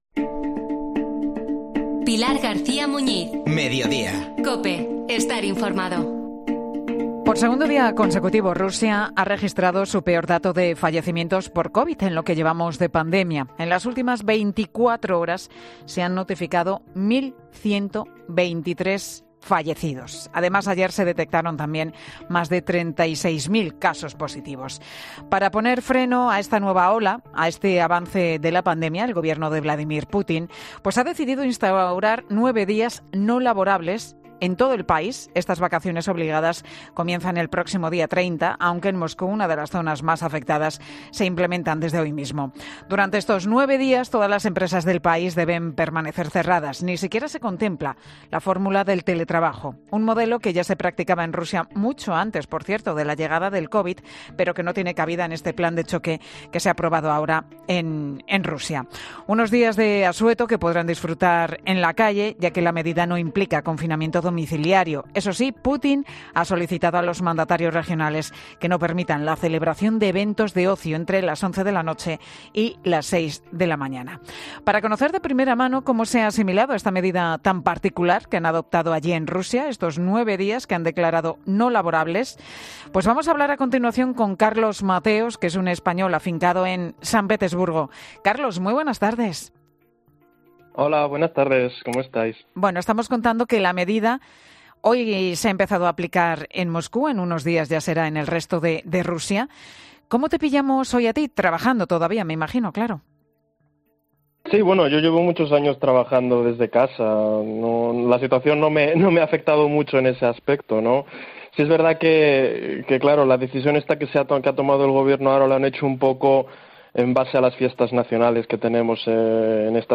Es español y está afincado en San Petersburgo